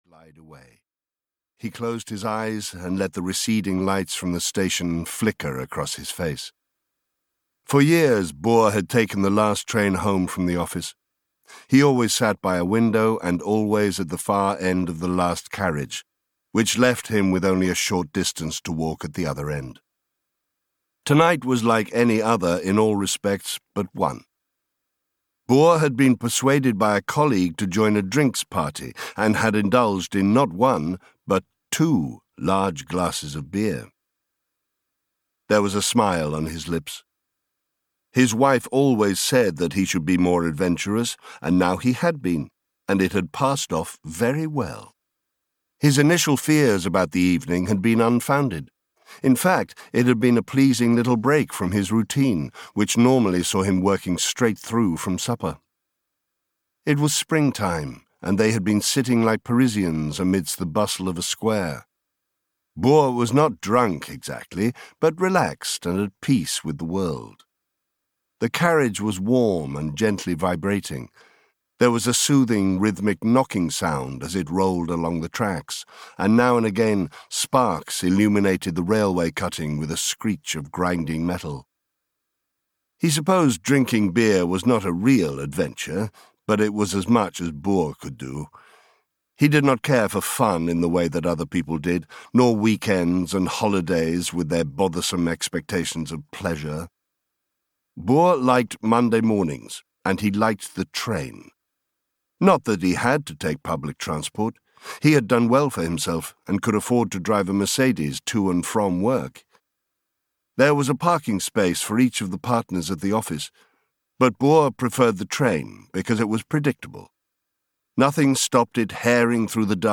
Last Train to Helsingør (EN) audiokniha
Ukázka z knihy
• InterpretTim Mcinnerny